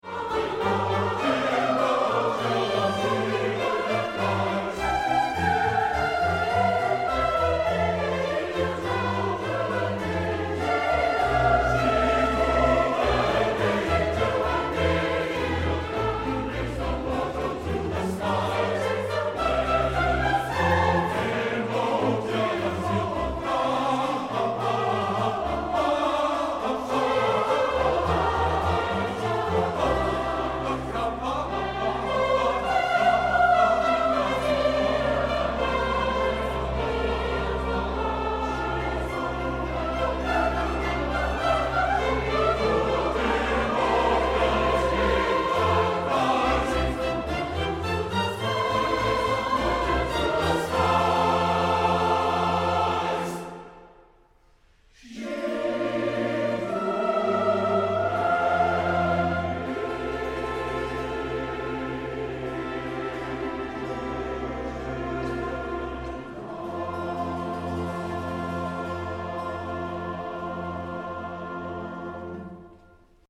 chorus and orchestra
in Sanders Theatre, Harvard University, Cambridge, MA